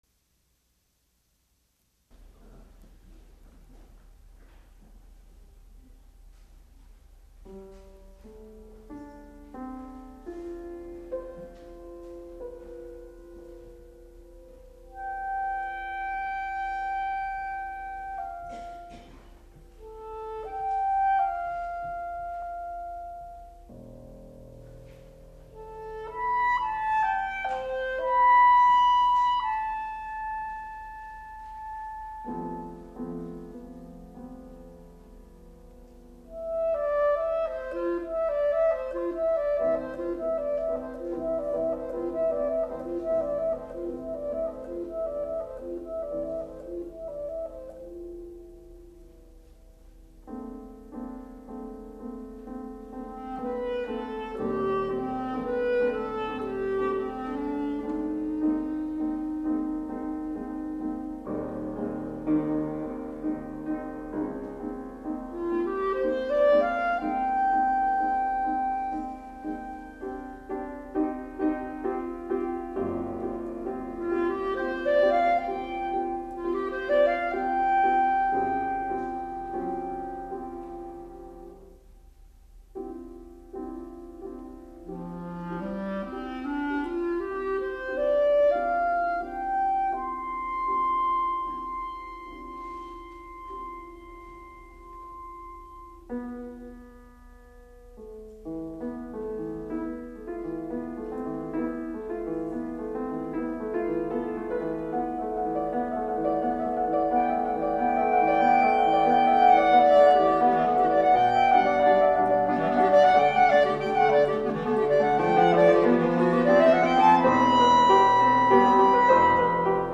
Reduction for clarinet and piano.